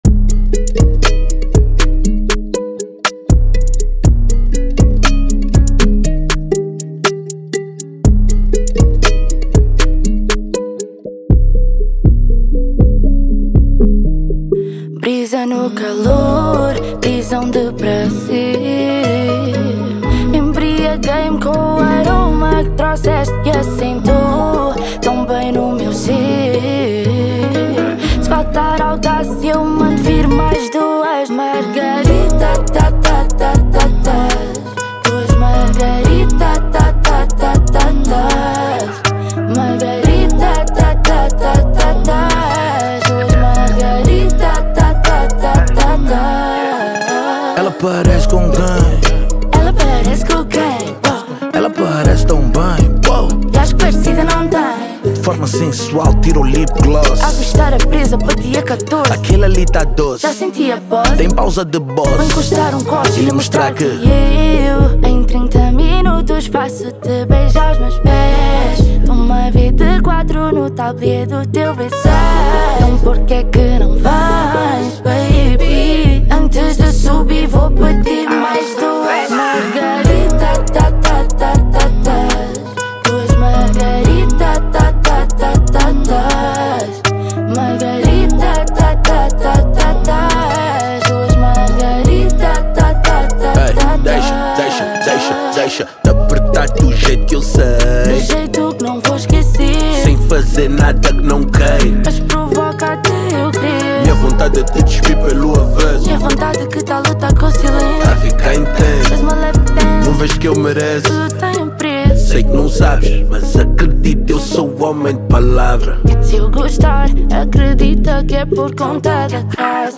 Genero: R&B/Soul